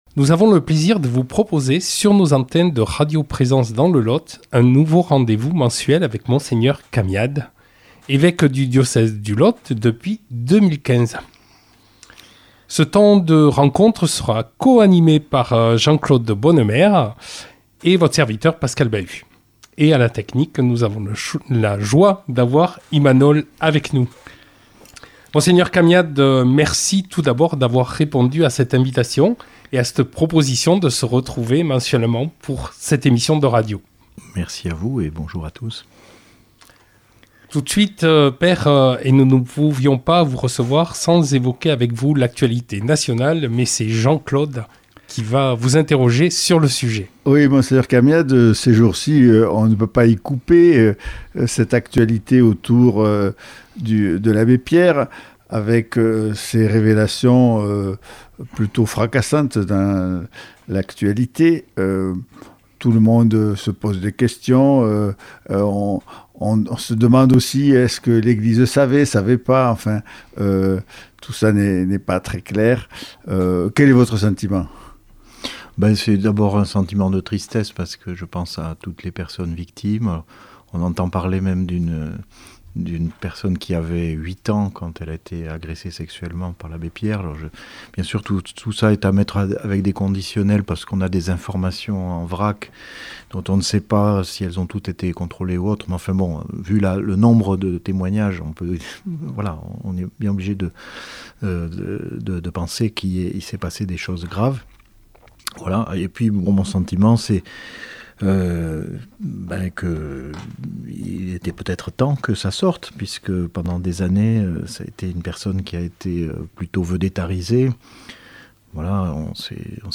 Entretien avec notre évêque